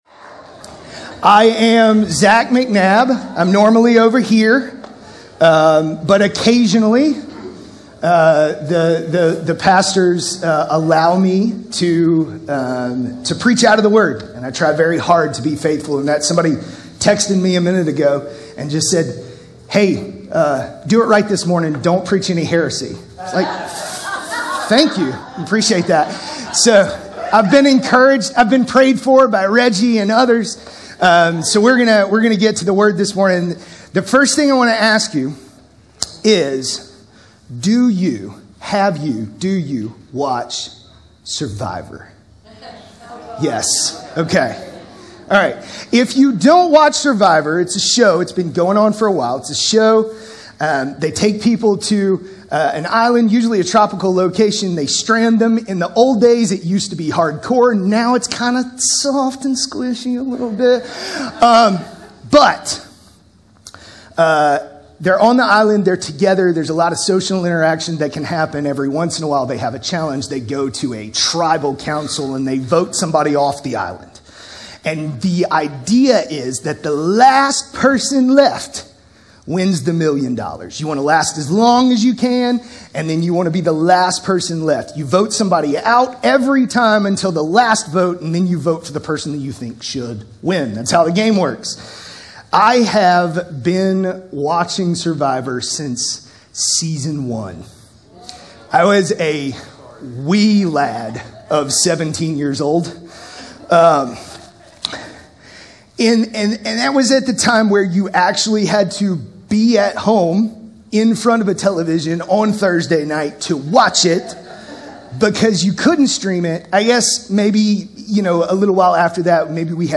This sermon examines: • The crafty wisdom of generosity • The inevitable exposure of wealth • The tragic misery of closed gates Jesus teaches that our relationship to money is never neutral.